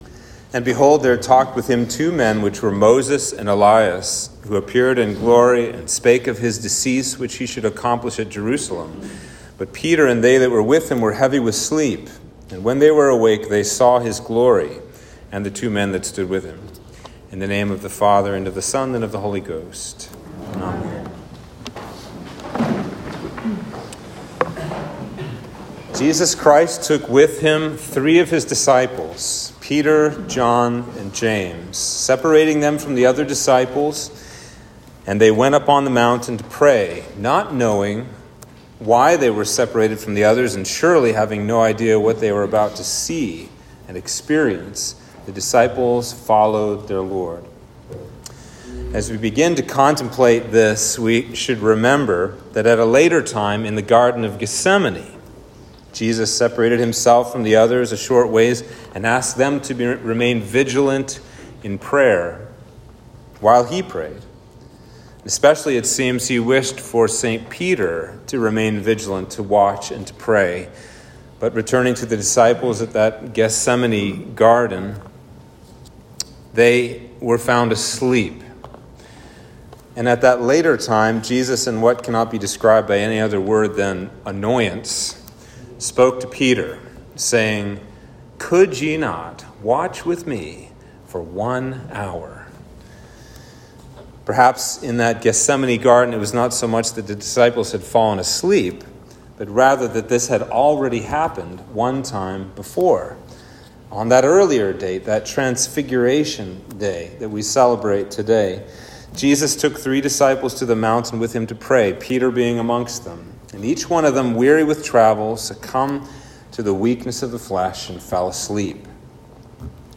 Sermon for Transfiguration